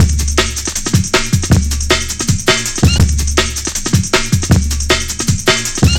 Index of /90_sSampleCDs/Zero-G - Total Drum Bass/Drumloops - 1/track 17 (160bpm)